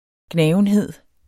Udtale [ ˈgnæːvənˌheðˀ ]